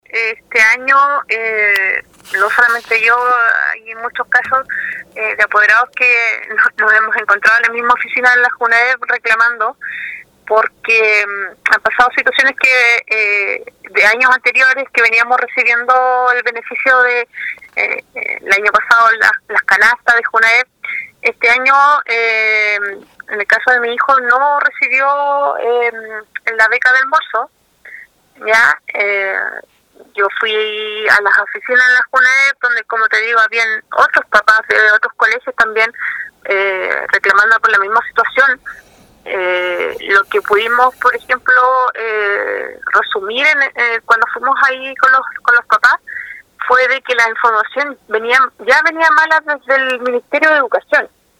De este modo lo planteó una apoderada de la comuna de Castro, quien describió el problema que enfrenta en este reinicio de la actividad presencial escolar, con su hijo sin el beneficio que hasta el año pasado recibía de parte e Junaeb.